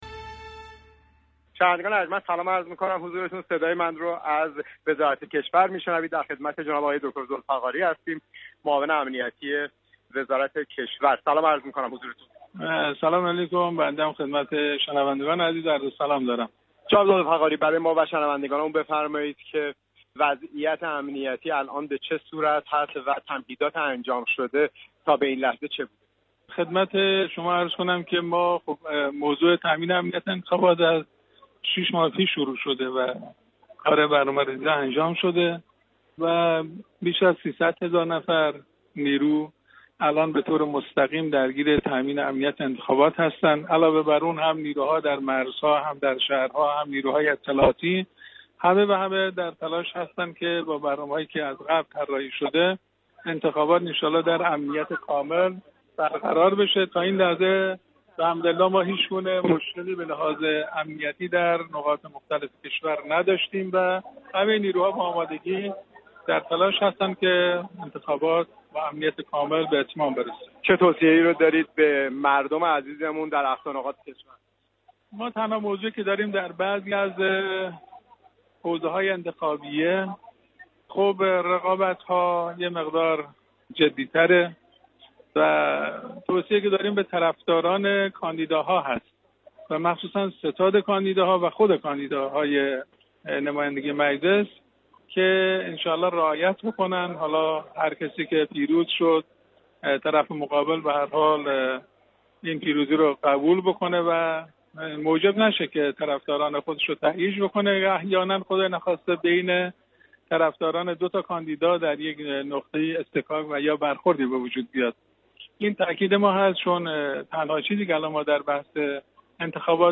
گفت و گوی اختصاصی